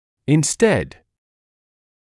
[ɪn’sted][ин’стэд]вместо, вместо этого, взамен